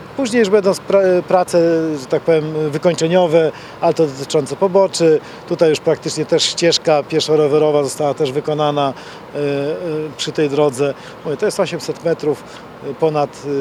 – Koszt prac to 860 tysięcy złotych – mówi Marek Chojnowski, starosta powiatu ełckiego.